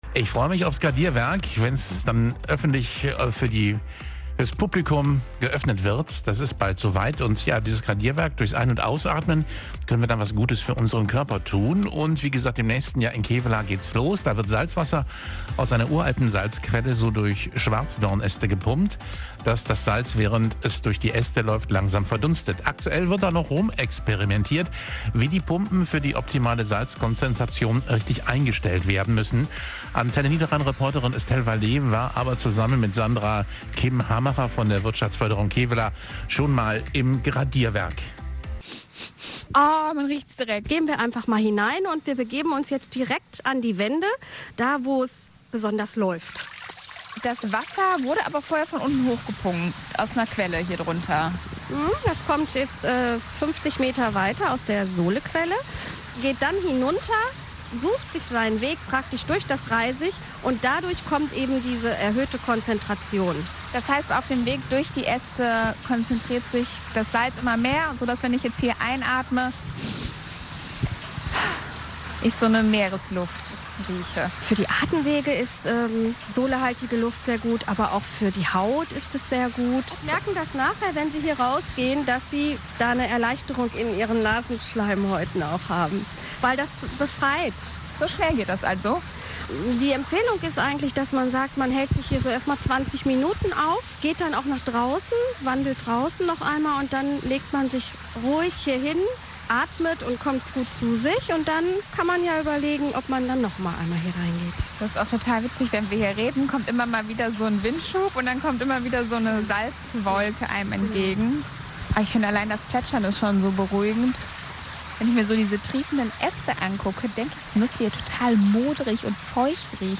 Rundgang durch's Gradierwerk in Kevelaer